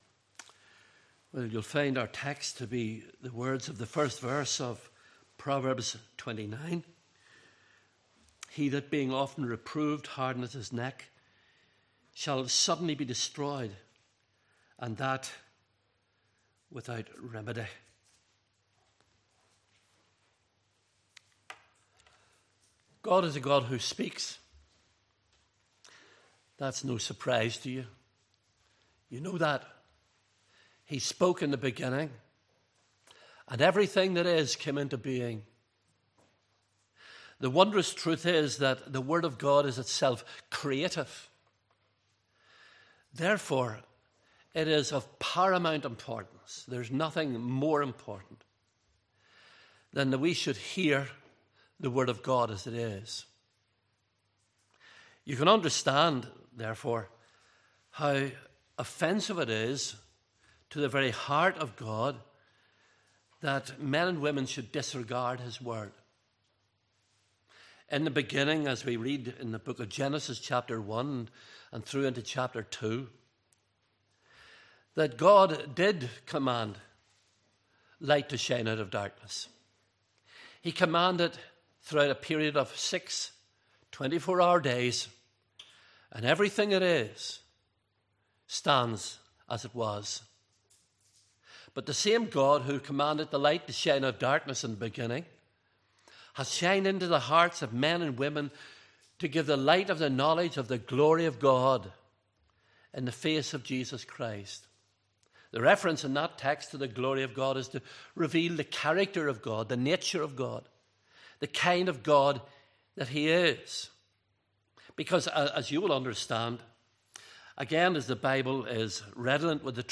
Proverbs 29:1 Service Type: Evening Service Bible Text